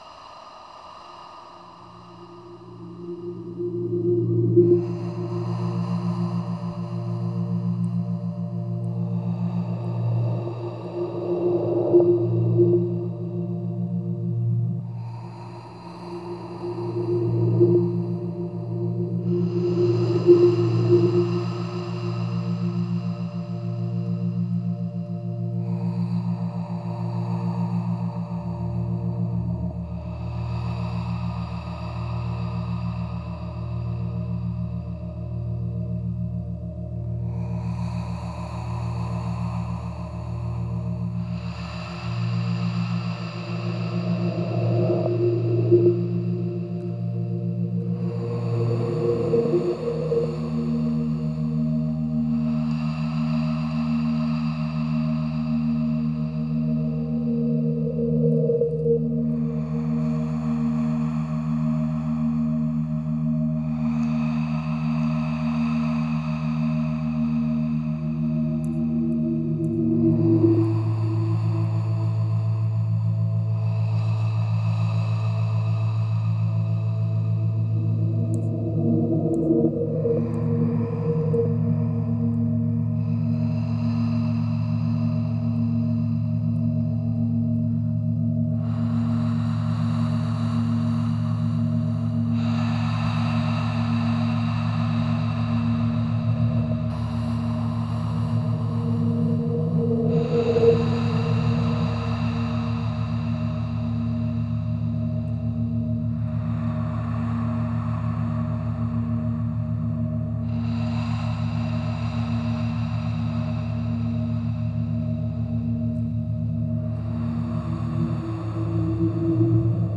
under_breath – 3 of 4 sound tracks of the installation
edera-e-respiri-1.wav